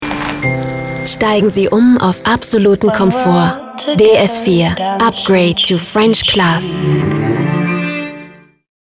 sehr variabel
Jung (18-30)
Sächsisch
Commercial (Werbung)